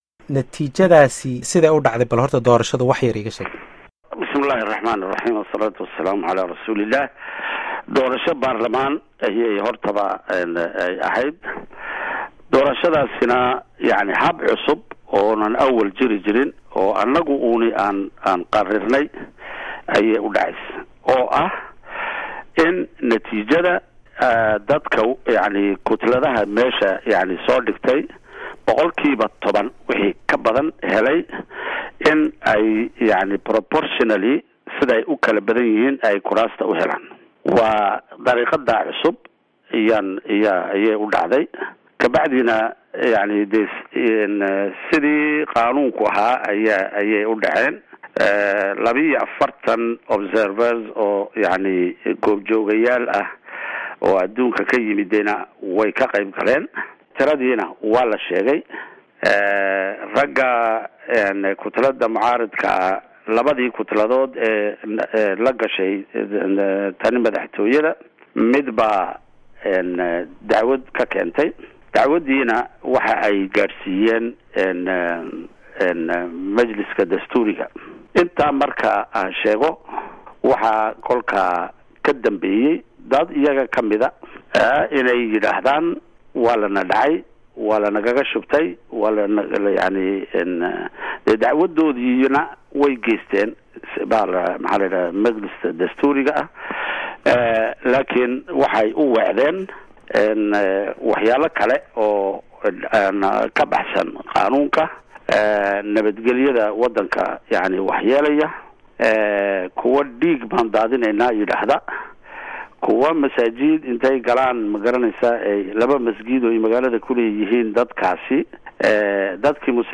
Wareysi Gaar ah Madaxweyne Ismaaciil Cumar Geelle